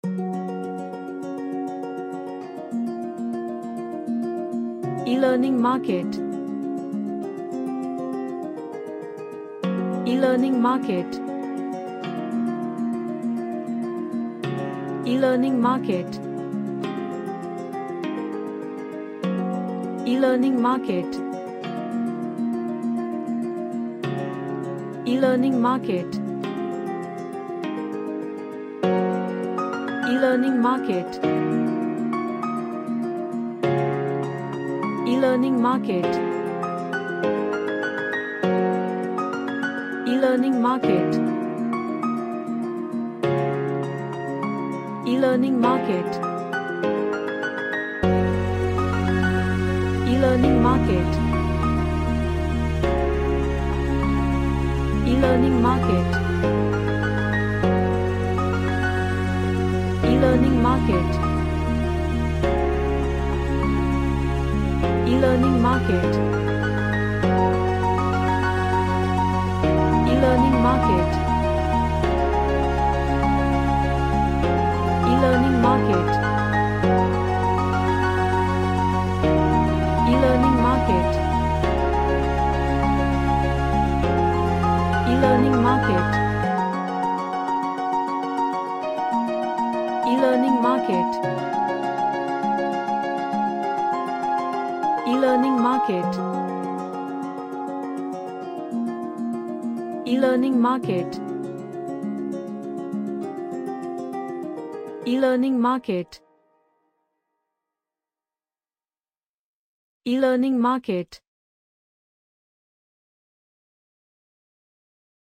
A happy cheerful moombahton dance track
Happy / Cheerful